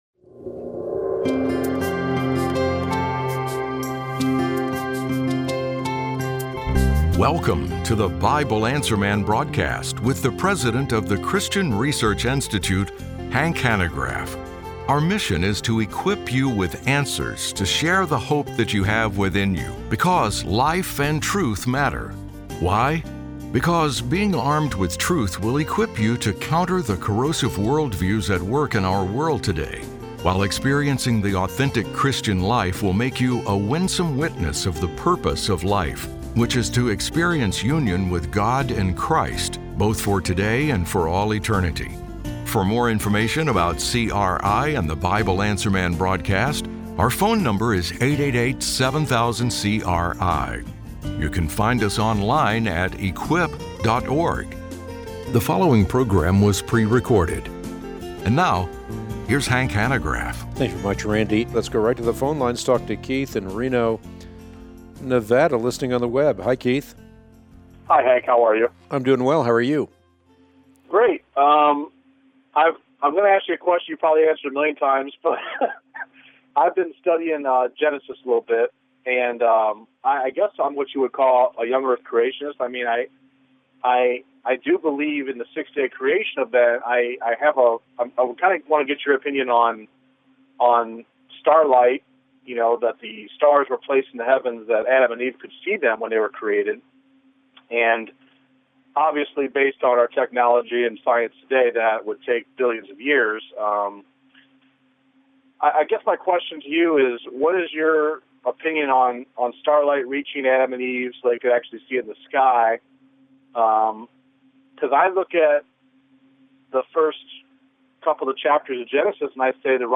On today’s Bible Answer Man broadcast (05/05/25), Hank answers the following questions: